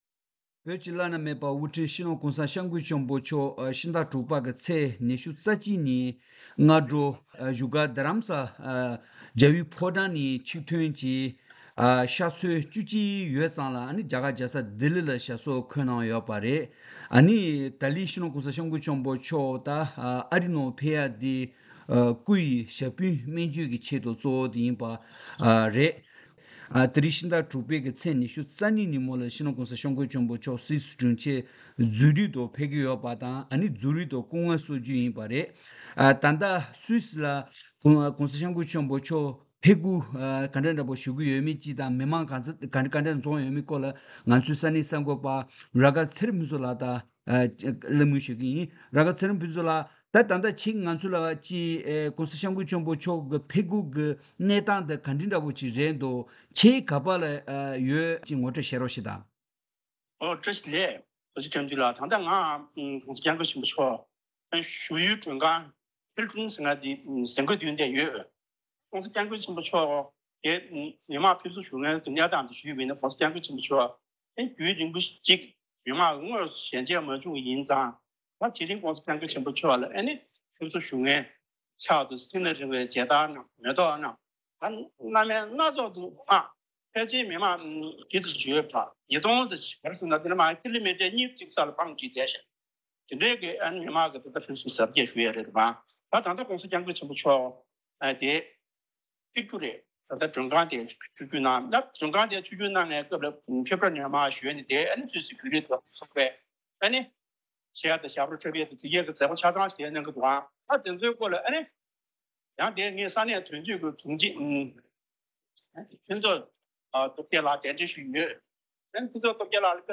བཅའ་འདྲི་བྱེད་པ་དེ་ཉན་རོགས་ཞུ།